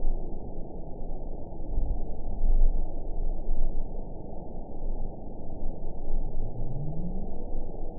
event 921106 date 04/28/24 time 16:52:42 GMT (1 year ago) score 9.15 location TSS-AB03 detected by nrw target species NRW annotations +NRW Spectrogram: Frequency (kHz) vs. Time (s) audio not available .wav